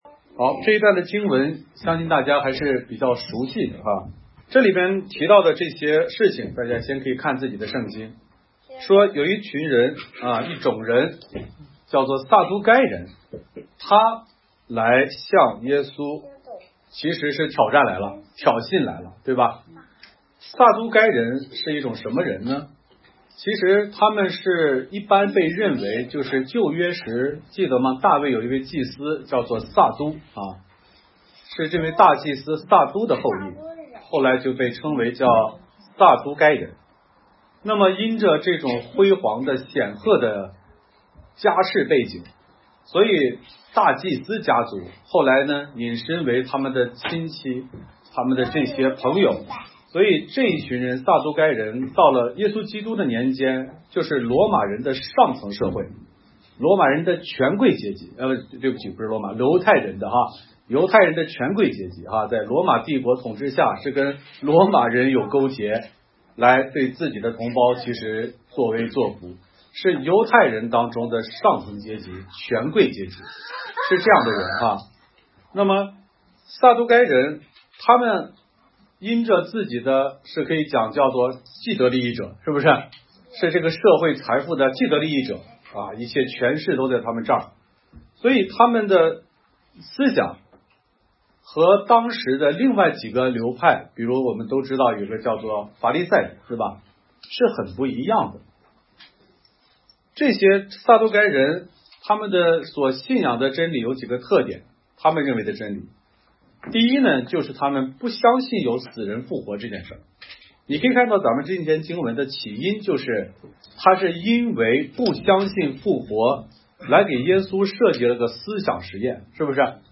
讲章